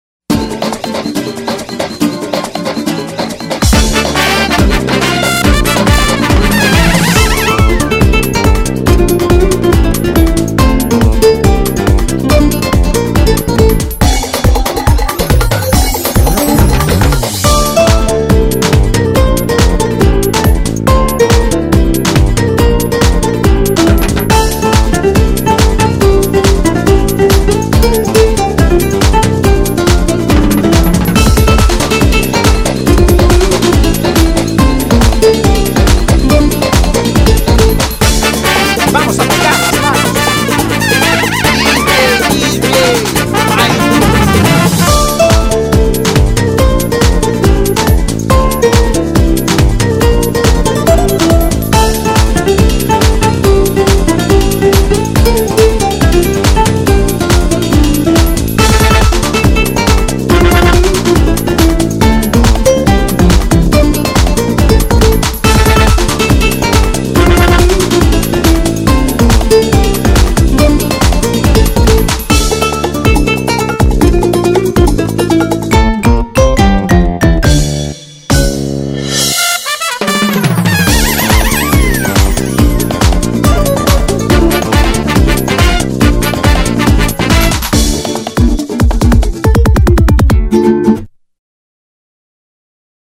기타 연주곡인데
곡 설명을 드리자면....진짜 기타 리듬이 멕시코 전통 음악 스타일같이 잘 써진듯 합니다.